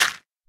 sounds / dig / gravel4.ogg
gravel4.ogg